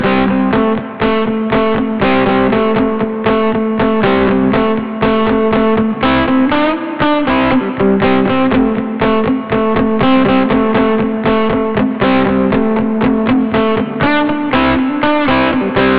Drum Percussion Loops » Drum Loop Rock01 120
描述：Recorded and processed using the following equipment: Interfaces:focusrite scarlett 2i2Alesis firewire io14 Microphones:Rode NT1000SE 2200 AAKG C1000sSuperlux R102 Phantom Powered Ribbon MicrophoneRadioshack PZM Pressure Zone Microphone X2Optimus 333022 Boundary Microphone X2Optimus 333017 Condenser MicrophoneRealistic Electret Condenser Stereo Microphone 33919ACustom Made PZM Panasonic condensors with custom 48volt phantom units X2Clock Audio C 009ERF boundary MicrophoneSony Stereo Electret Condenser Microphone ECM99 AOktaver IIMK55Oktava mke2AKG D95sBeyer Dynamic M58Various Vintage Microphones Portable Units:Olympus VN8600PCZOOM H2 Misc Equipment:Phonic MU802 MixerBehringer Behringer UCONTROL UCA222Korg Toneworks AX100GVarious Guitar Pedals Software:ReaperAudacity
标签： loop hit rock rhythm percussive 120bpm percussion drums beat percussionloop quantized drumloop drum groovy
声道立体声